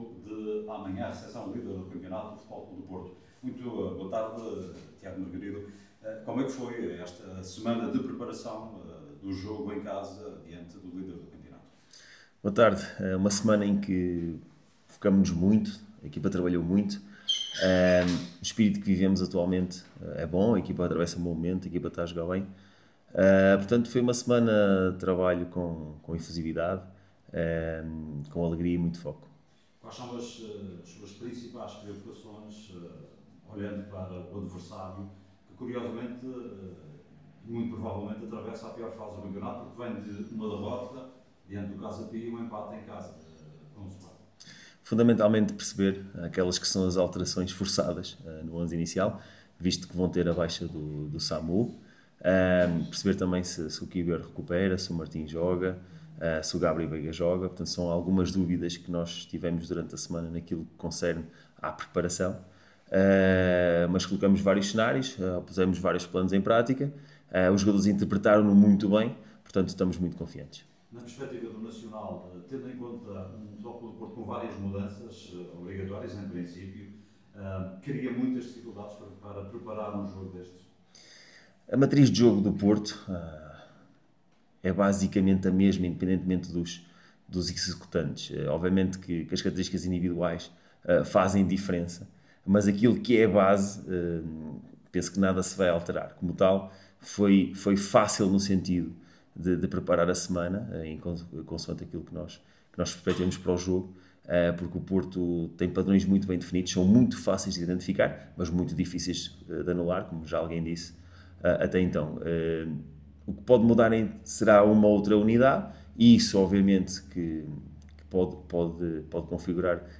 Na conferência de imprensa de antevisão à partida